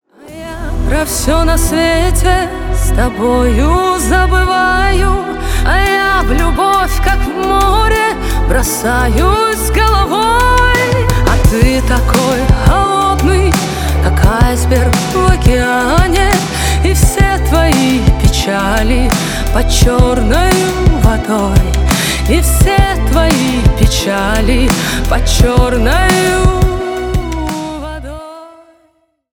Поп Музыка
кавер
спокойные